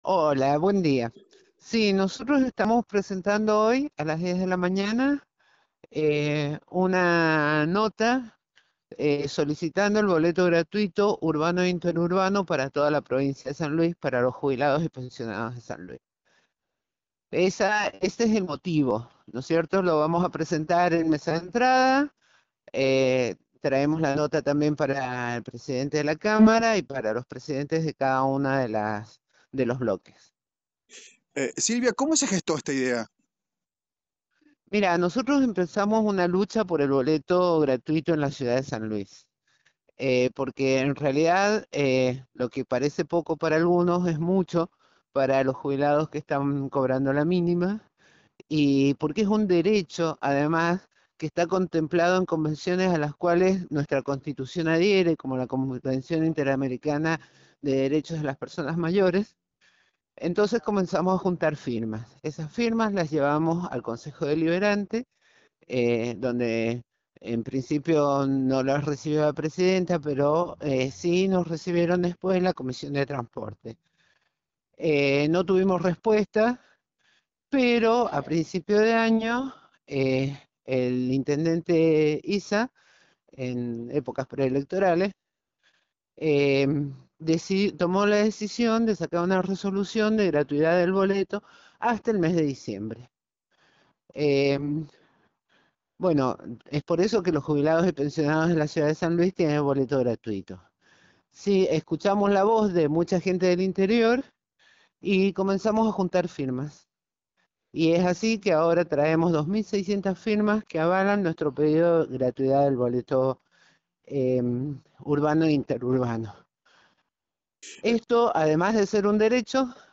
habló en exclusiva con el móvil de Radio La Bomba y comentó todos los detalles del proyecto que presentarán en el Poder Legislativo para que sea tratado.